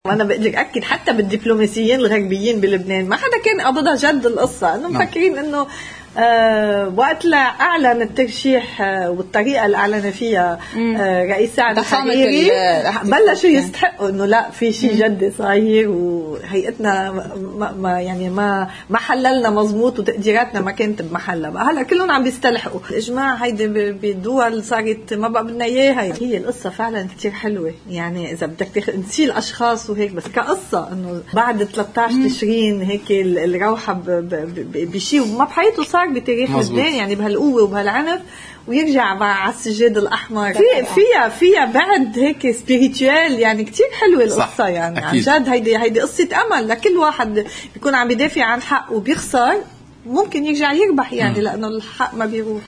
في حديث الى قناة الـ”OTV”